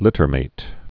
(lĭtər-māt)